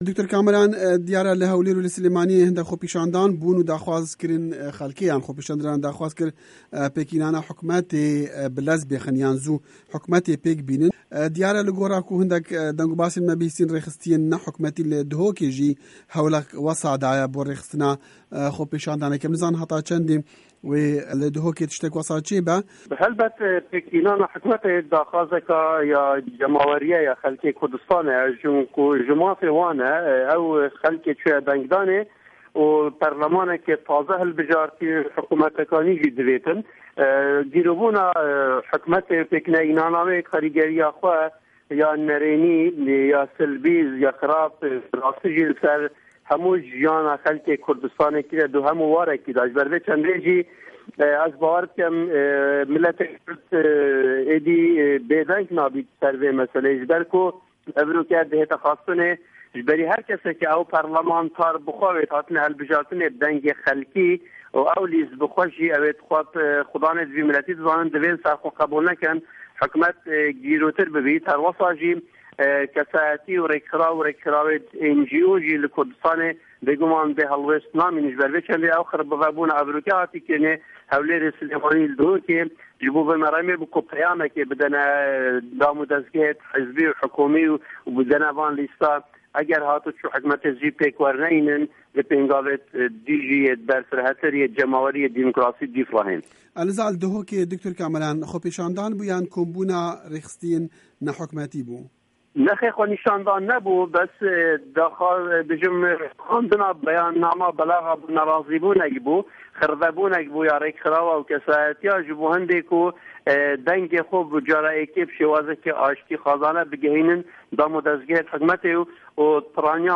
Hevpeyivin